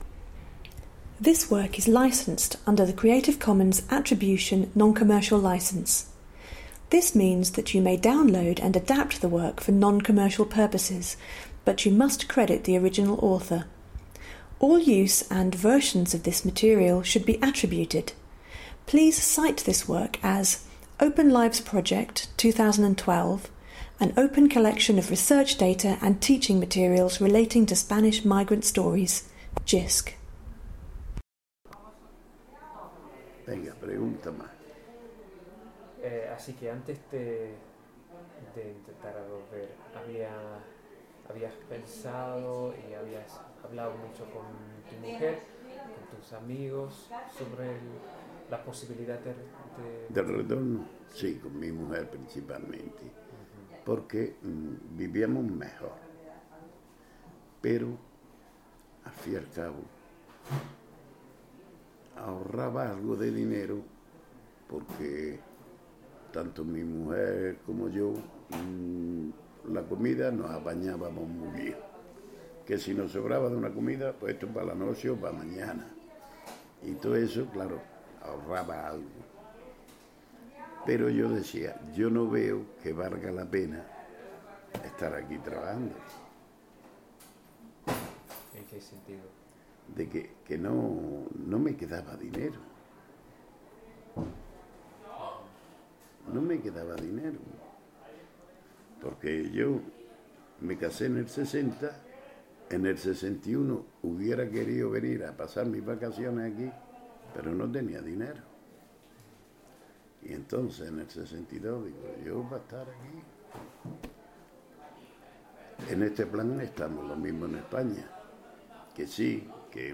OpenLIVES Spanish Emigre interviews